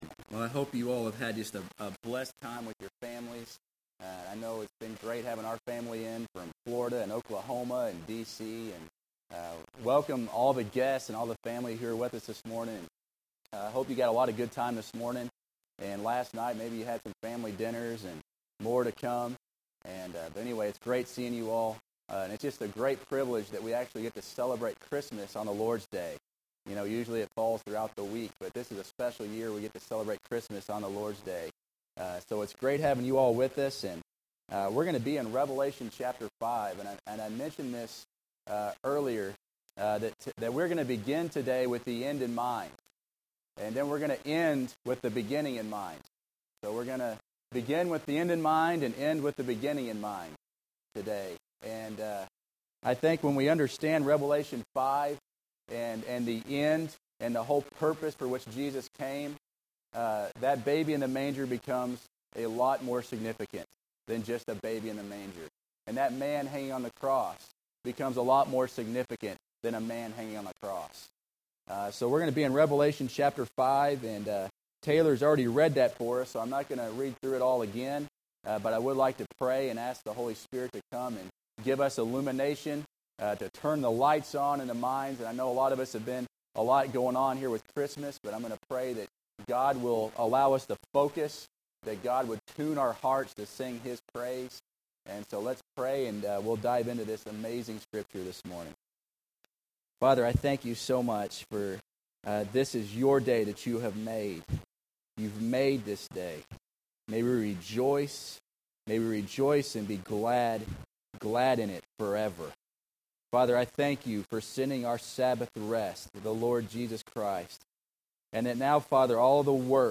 Passage: Revelations 5 Service Type: Sunday Morning Bible Text